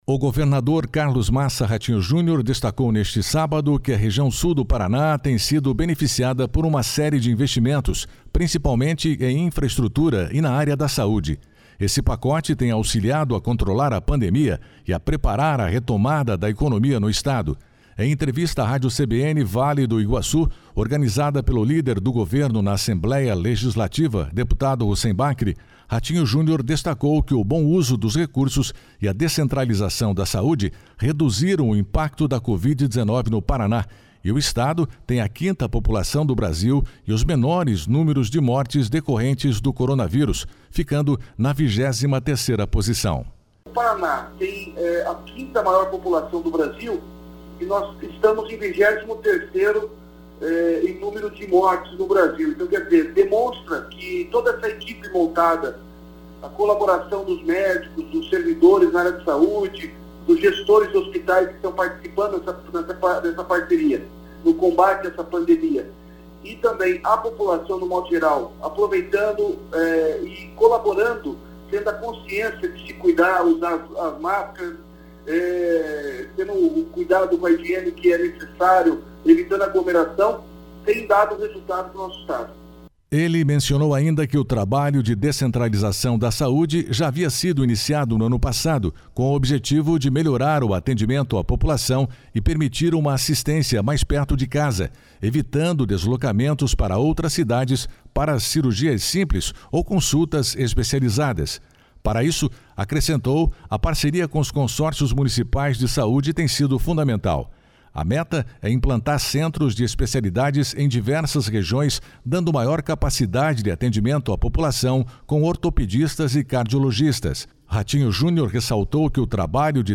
//SONORA RATINHO JUNIOR//
//SONORA HUSSEIN BAKRI//